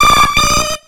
Cri d'Héliatronc dans Pokémon X et Y.